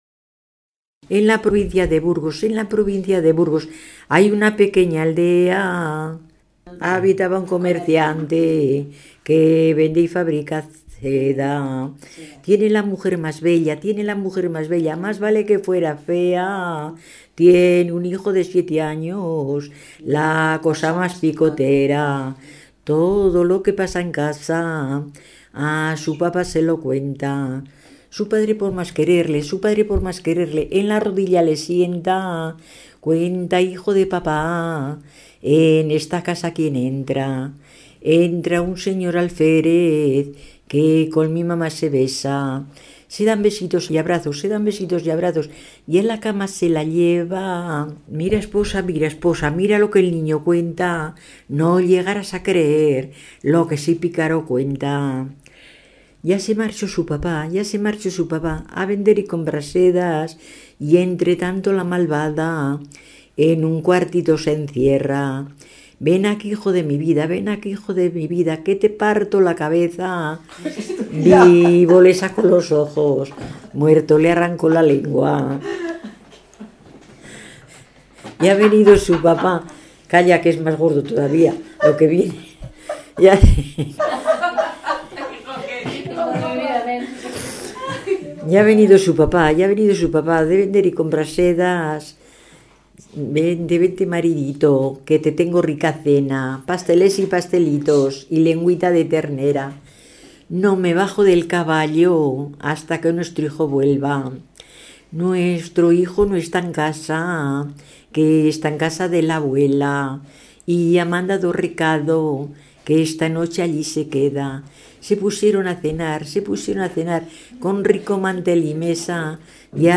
Clasificación: Romancero
Localidad: Villamediana de Iregua
Lugar y fecha de recogida: Logroño, 16 de julio de 2001